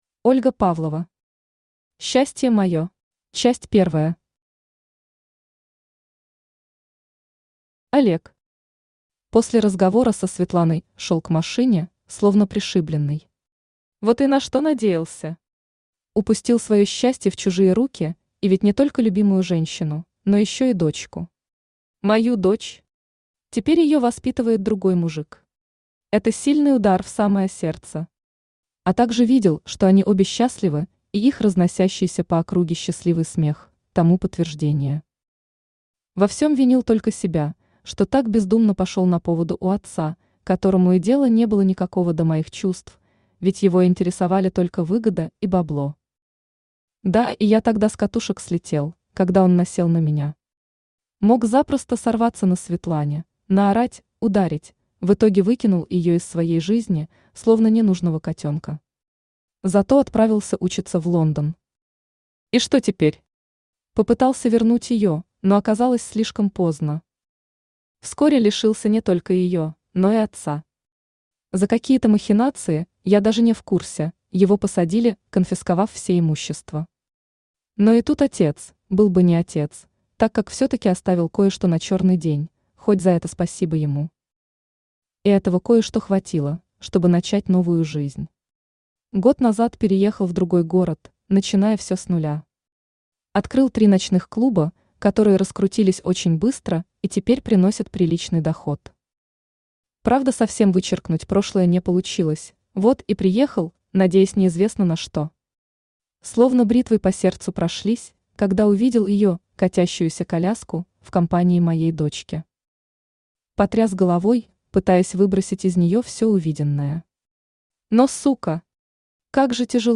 Aудиокнига Счастье мое Автор Ольга Анатольевна Павлова Читает аудиокнигу Авточтец ЛитРес.